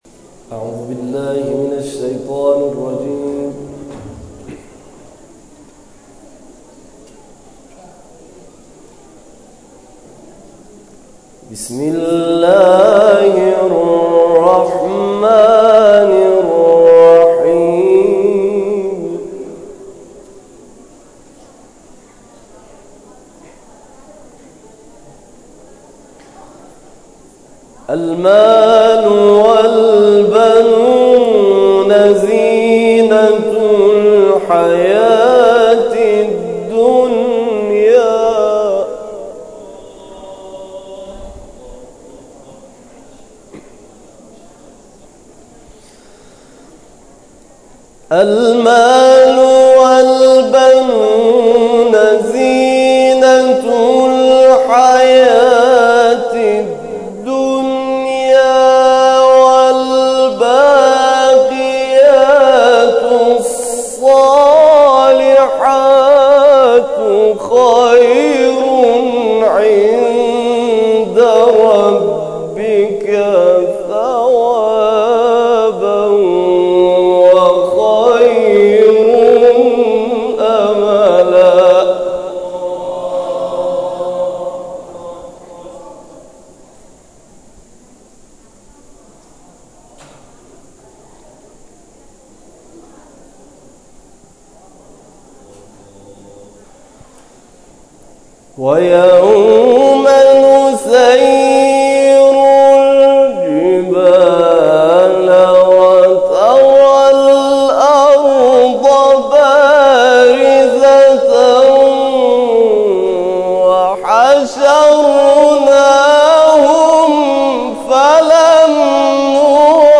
این کرسی ها در هفته گذشته، همزمان با آغاز دهه کرامت و ولادت حضرت معصومه(س)، با تلاوت قاریان ممتاز و بین المللی کشورمان همراه بود.
در ادامه تلاوت‌ها و گزارش تصویری این کرسی‌ها ارائه می‌گردد.